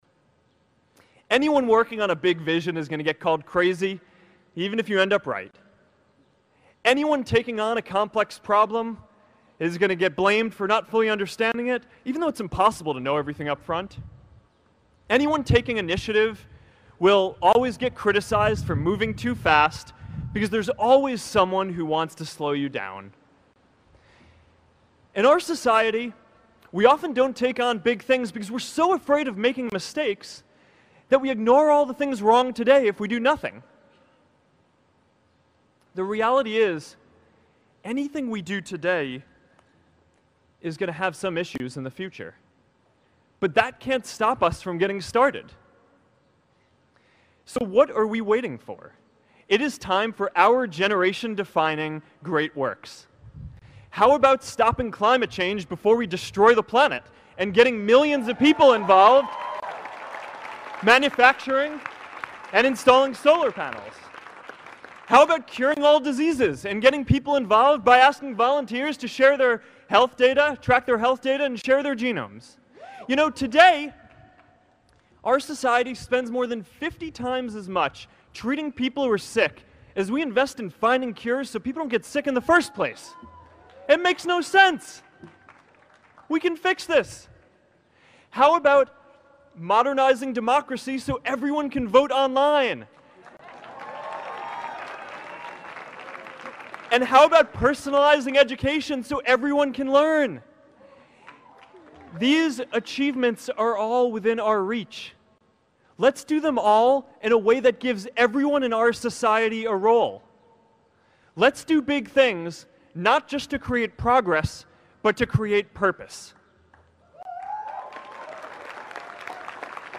公众人物毕业演讲 第463期:扎克伯格2017哈佛毕业演讲(7) 听力文件下载—在线英语听力室